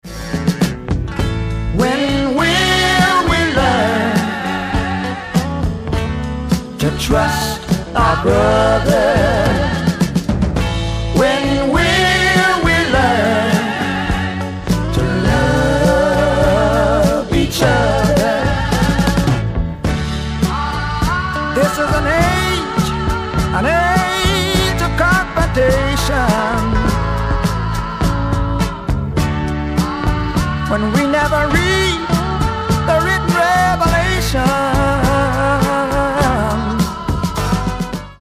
ソリッドなリズム・セクションとリズミカルなホーンをフィーチャーしたスワンプ・ロック傑作
ブルー・アイド・ソウル感溢れるふたりのヴォーカルのコンビネーションも最高の一枚！